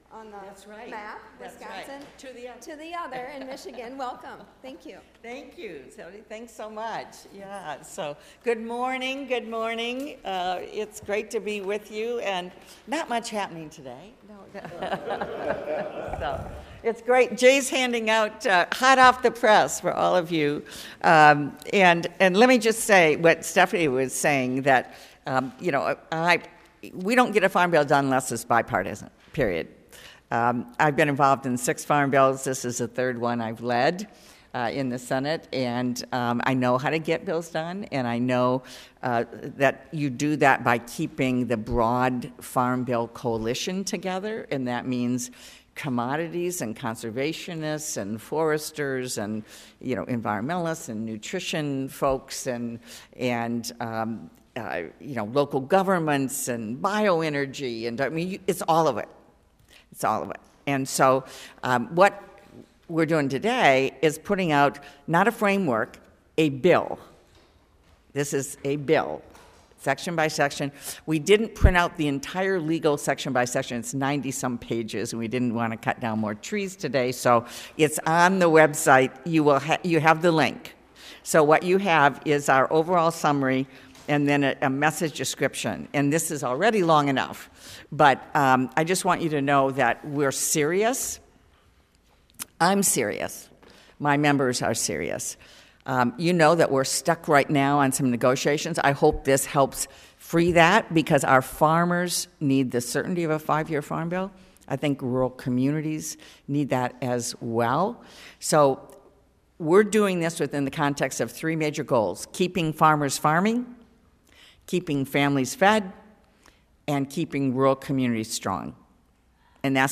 Listen to the Stabenow news briefing with members of the National Association of Farm Broadcasting .